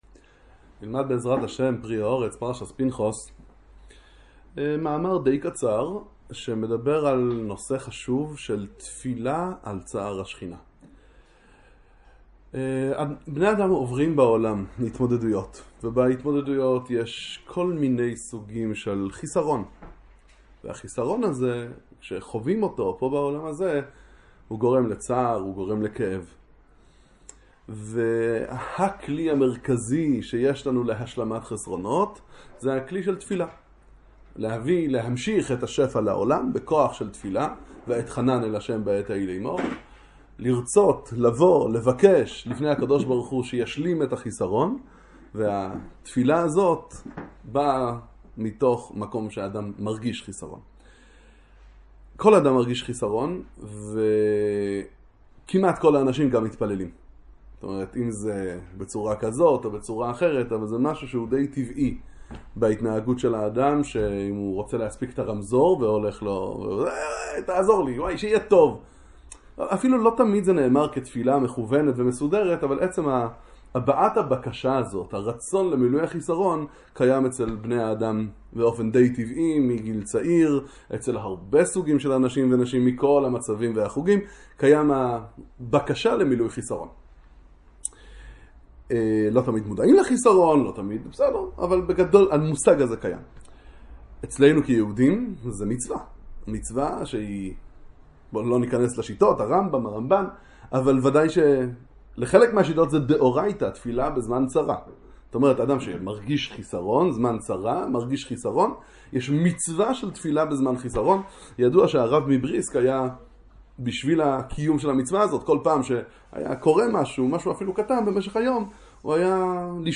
שיעור לצפיה בספר פרי הארץ, הדרכה לתפילה מספרי חסידות